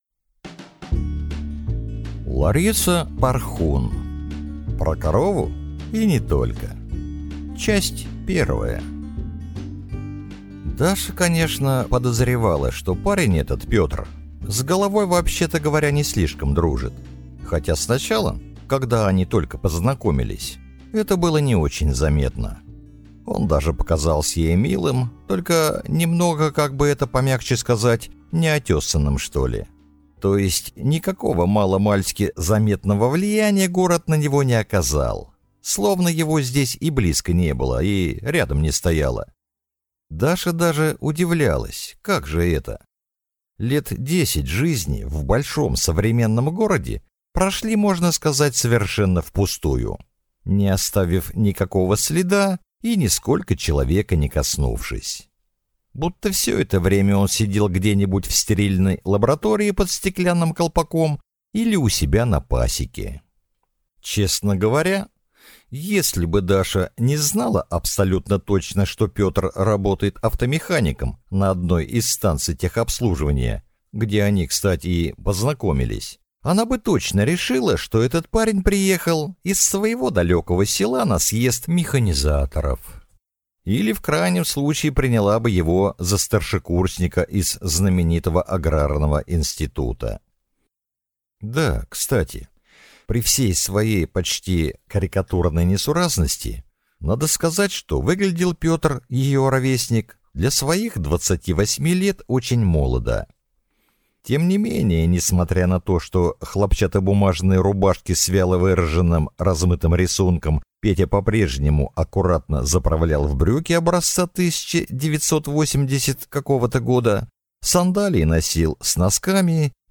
Аудиокнига Про корову и не только | Библиотека аудиокниг